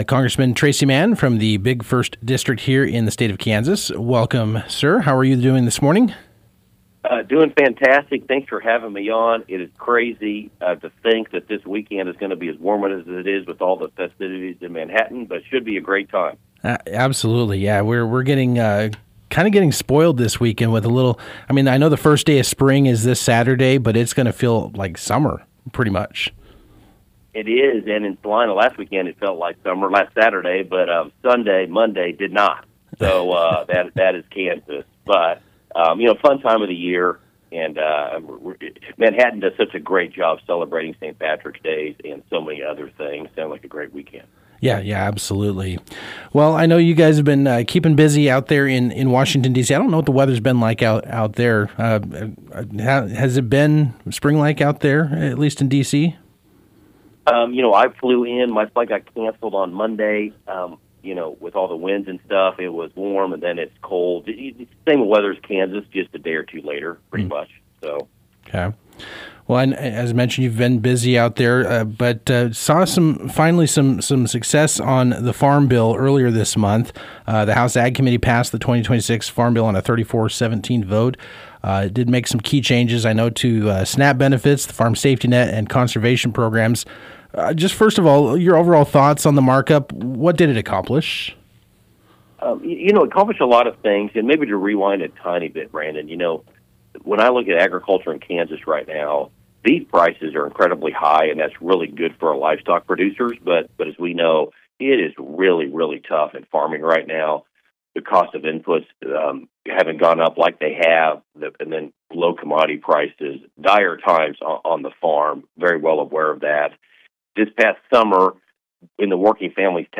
Kansas 1st District Congressman Tracey Mann called into the morning show Wednesday to highlight the House Ag Committee’s passage of Farm Bill 2.0, as well as updates on the DHS ongoing partial government shutdown and thoughts on the SAVE America Act voter ID bill.
0318-Tracey-Mann-Interview.mp3